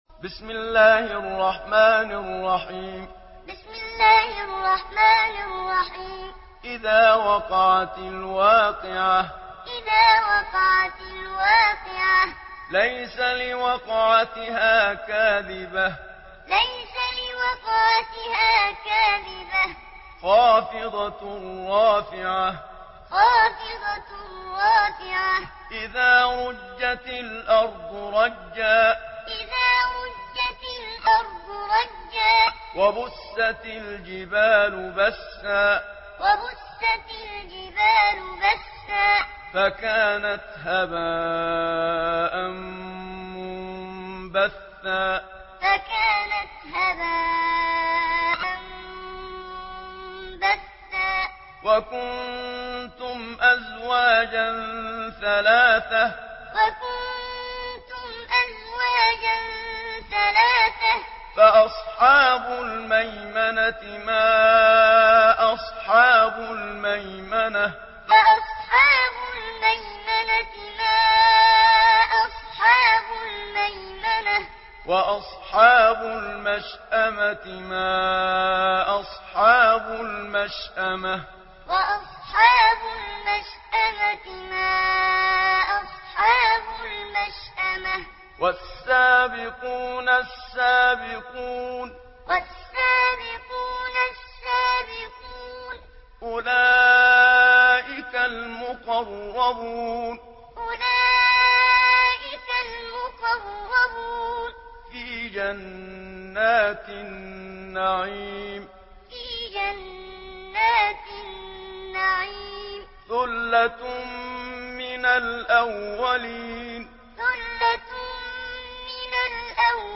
Surah Al-Waqiah MP3 in the Voice of Muhammad Siddiq Minshawi Muallim in Hafs Narration
Listen and download the full recitation in MP3 format via direct and fast links in multiple qualities to your mobile phone.